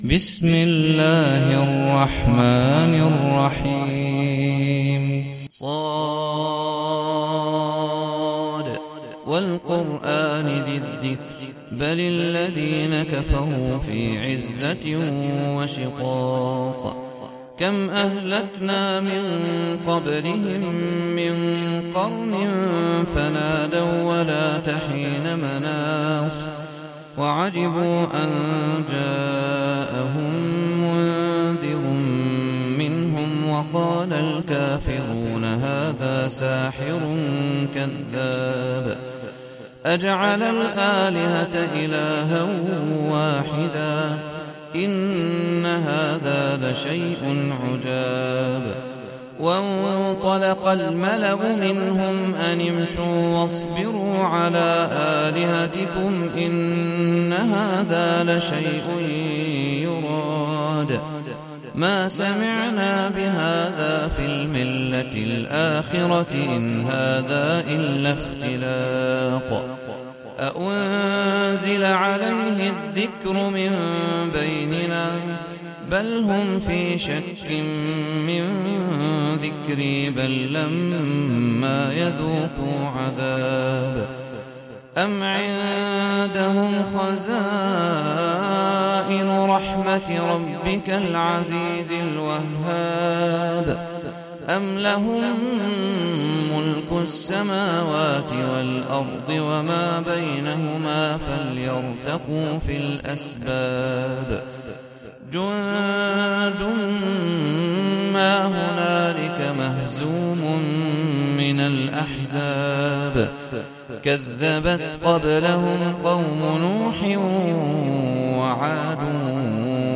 Sûrat Sad (The Letter Sad) - صلاة التراويح 1446 هـ (Narrated by Hafs from 'Aasem)